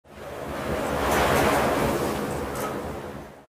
wind_gust1.mp3